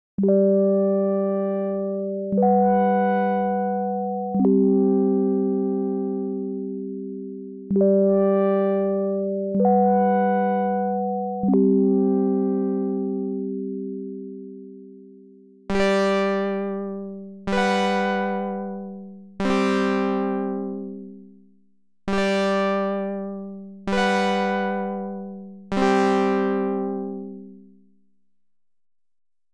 Demo Patch
On the right is an additive voice which responds to MIDI channel 2. The mod wheel is pacthed to control feedback which should influence the brightness of the sound.
The mod wheel is patched to allow a Low-Frequency Oscillator (LFO) to frequency modulate the VCO for a vibrato effect.
This is what it sounds like - we first hear the additive patch on channel 2, then the subtractive one on channel 3.
Not very exciting, I know — it's just to demonstrate the principle.